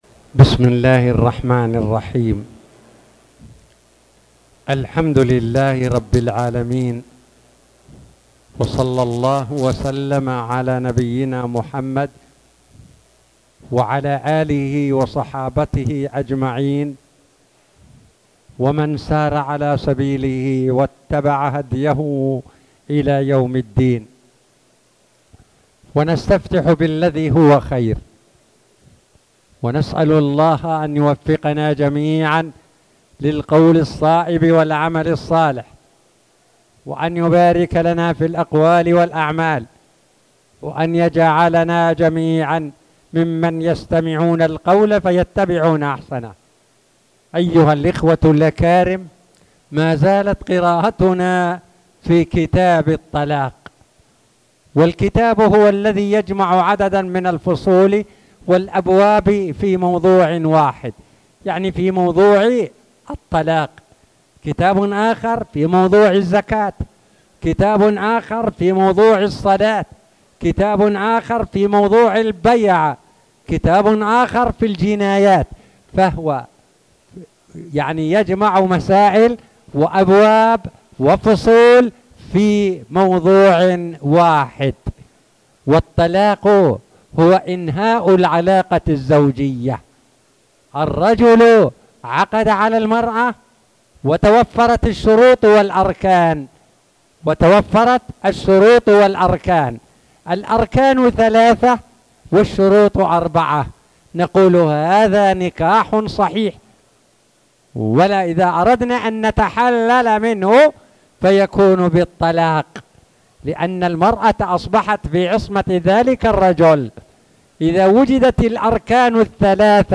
تاريخ النشر ٥ رجب ١٤٣٨ هـ المكان: المسجد الحرام الشيخ